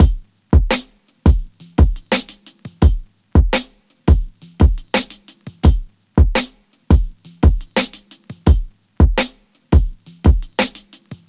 DrumNBass
描述：鼓和低音,鼓的节拍。
Tag: 175 bpm Drum And Bass Loops Drum Loops 296.27 KB wav Key : Unknown